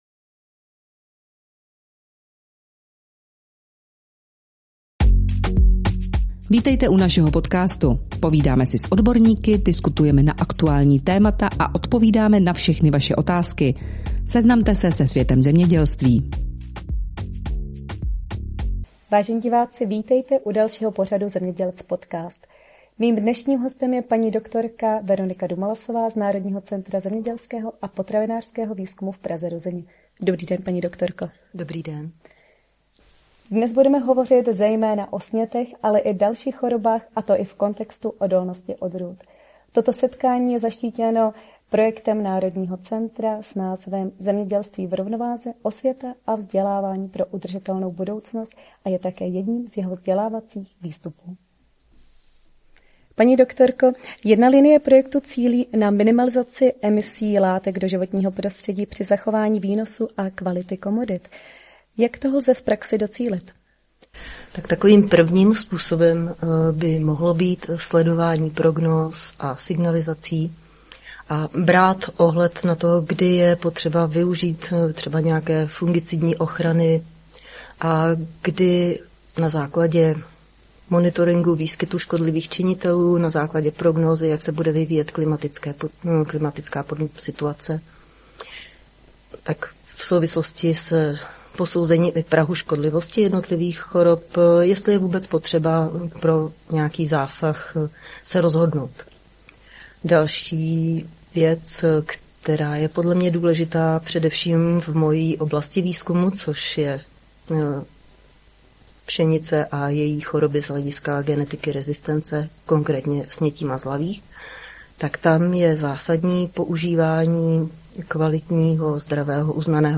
Odborná diskuse